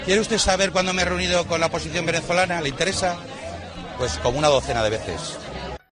Declaraciones del ministro